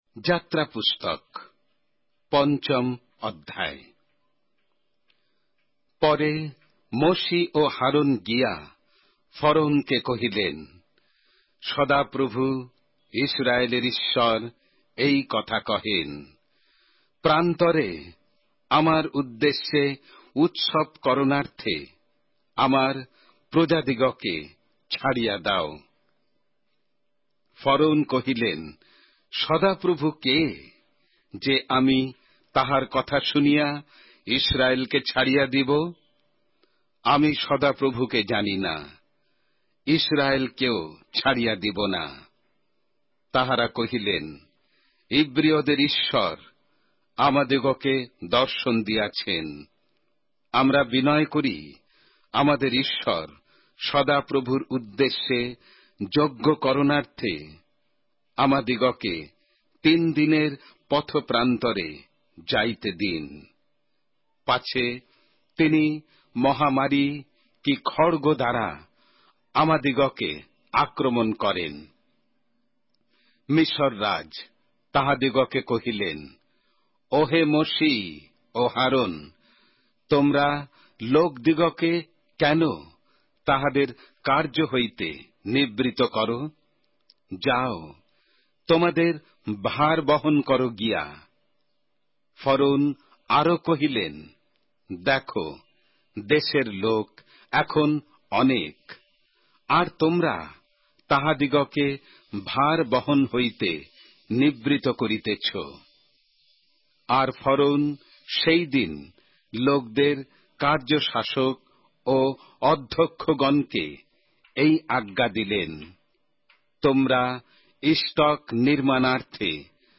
Exodus, chapter 5 of the Holy Bible in Bengali:অডিও আখ্যান সঙ্গে বাংলা পবিত্র বাইবেল অধ্যায়,